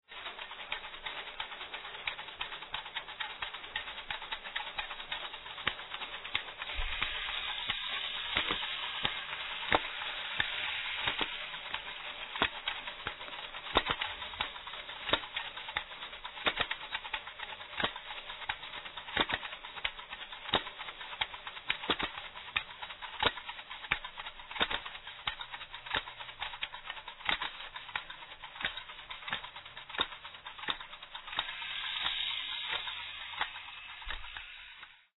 traditional Malagasy songs